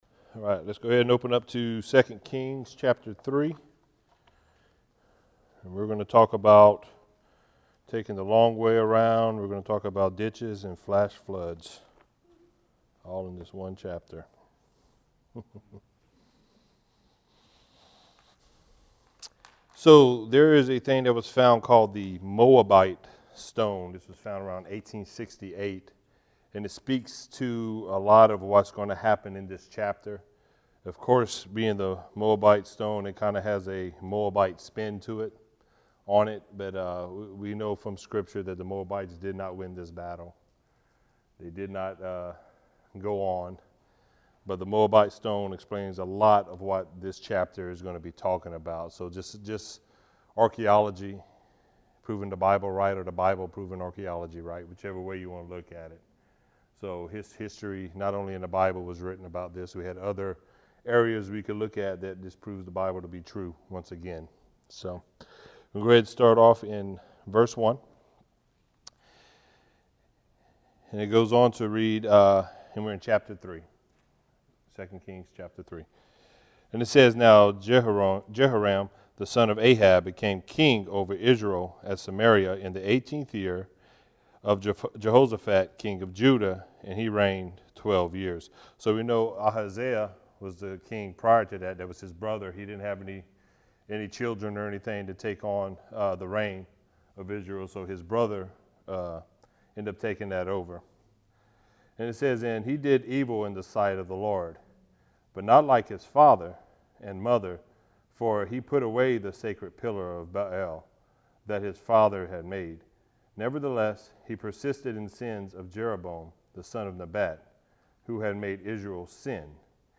Join us for this verse by verse study in the book of 2 Kings